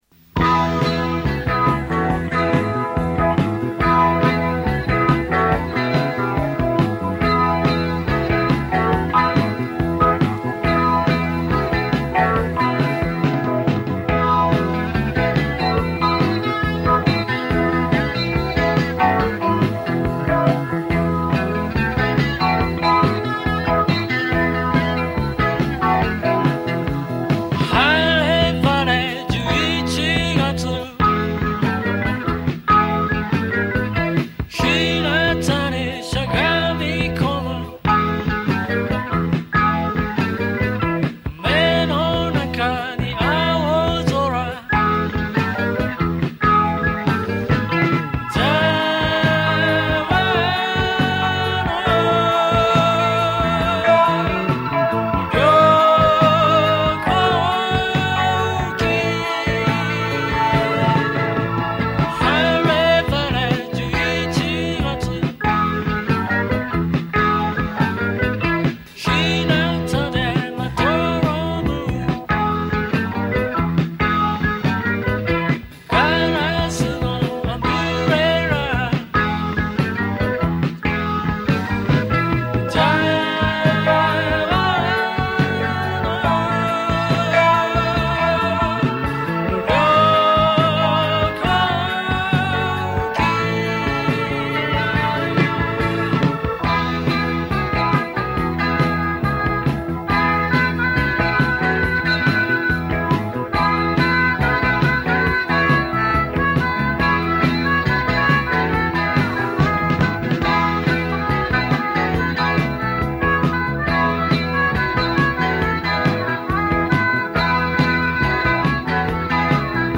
Guitar
Organ